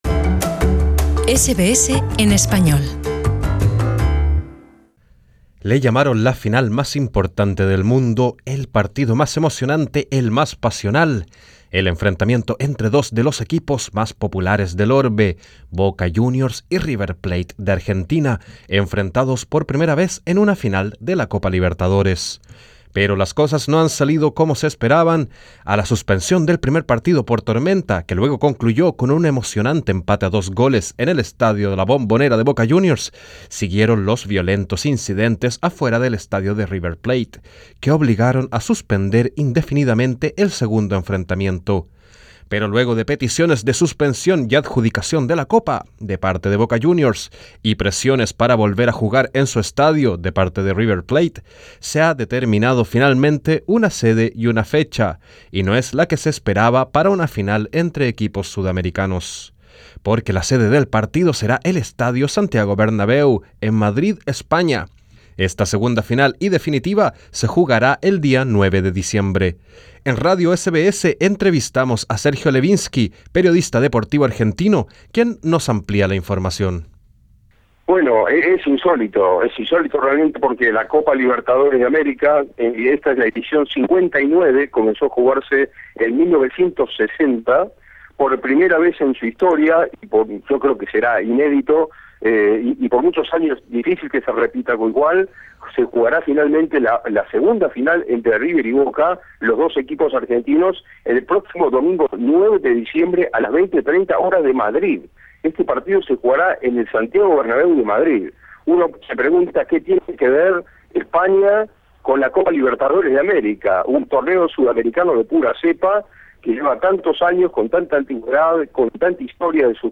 SBS Spanish